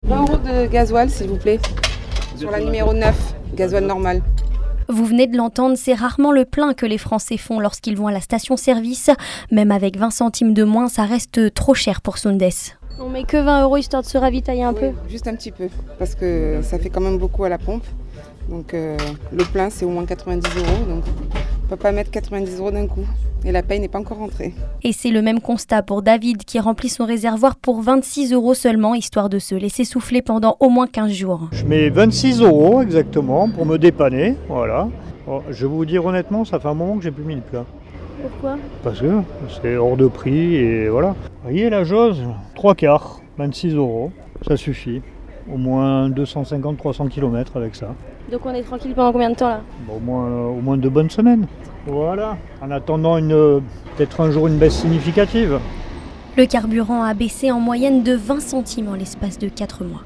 Mais pour d’autres, c'est encore trop cher pour faire un plein systématiquement. Ecoutez ces Niçois.